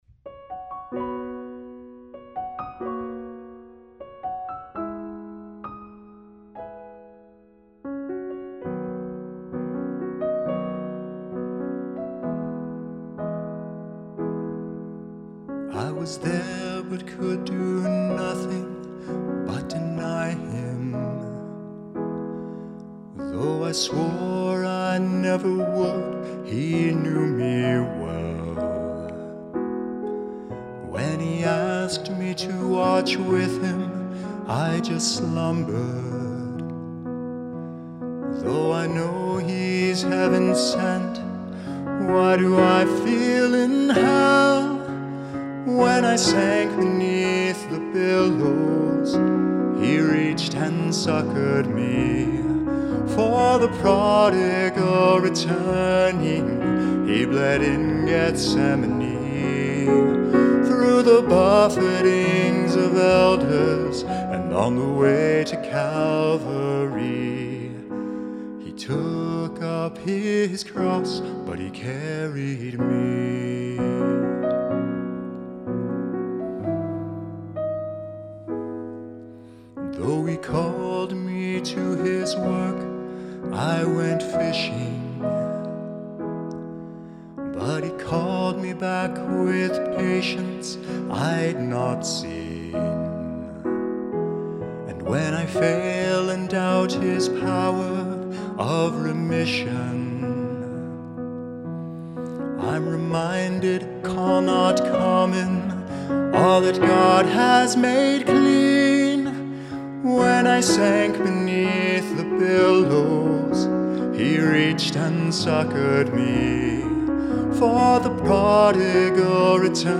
Original Key of B Major